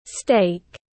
Bít tết tiếng anh gọi là steak, phiên âm tiếng anh đọc là /steɪk/
Steak /steɪk/